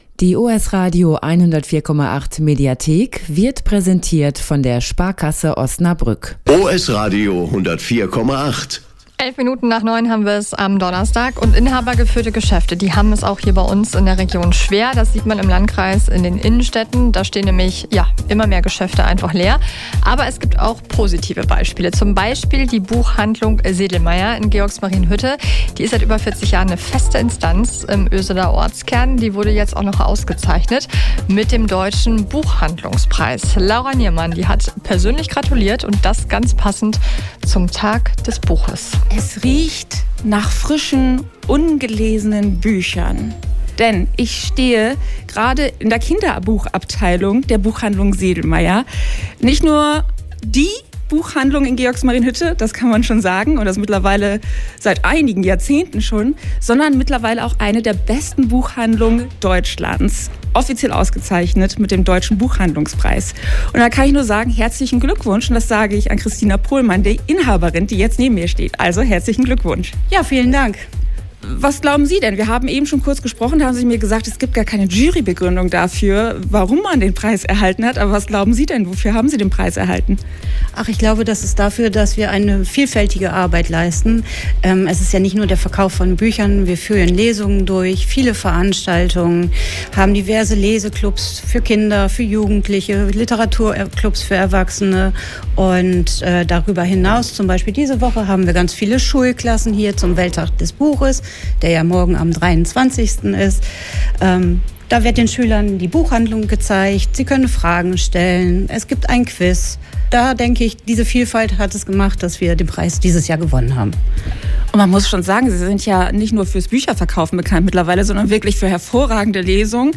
Eine davon ist die Buchhandlung Sedlmair in Georgsmarienhütte: Seit über 40 Jahren gehört sie fest zum Ortskern in Oesede – und wurde jetzt mit dem Deutschen Buchhandlungspreis ausgezeichnet. Warum sich die Buchhandlung so erfolgreich behauptet und was hinter der Auszeichnung steckt, hört ihr im Mitschnitt – inklusive eines besonders schönen Fazits von den jüngsten Leserinnen und Lesern.